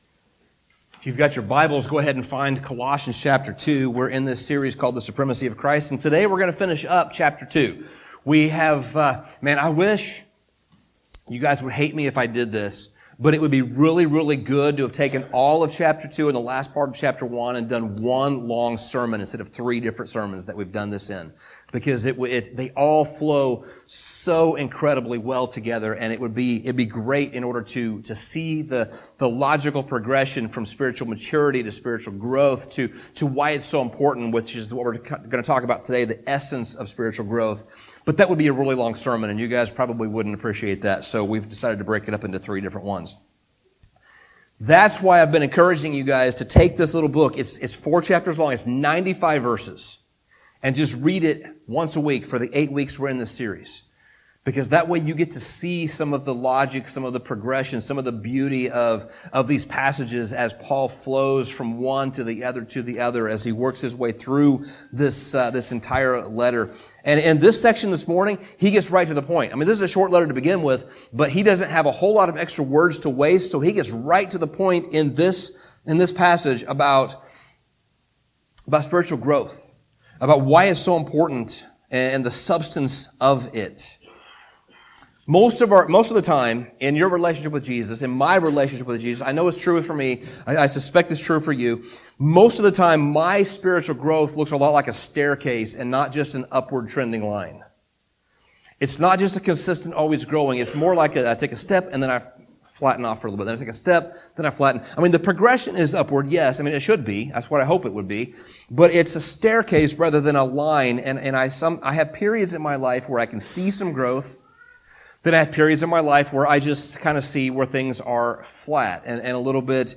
Sermon Summary The latter part of Colossians 2 seems a little tricky to understand.